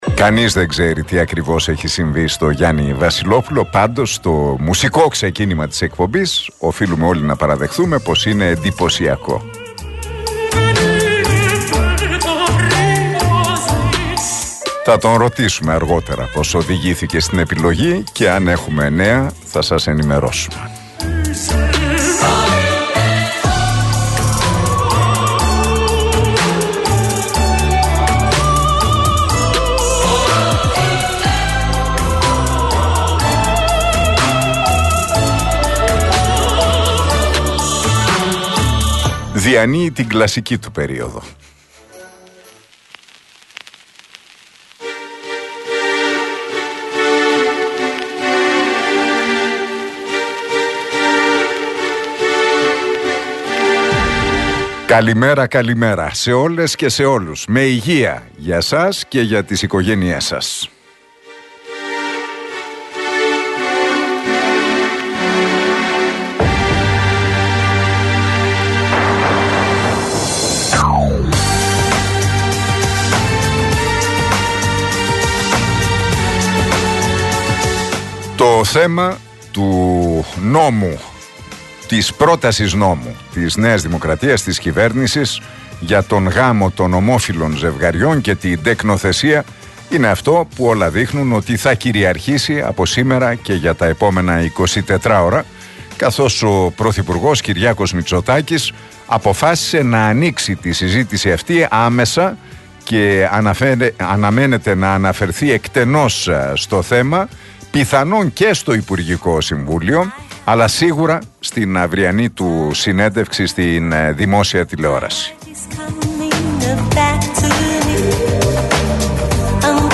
Ακούστε το σχόλιο του Νίκου Χατζηνικολάου στον RealFm 97,8, την Τρίτη 9 Ιανουαρίου 2024.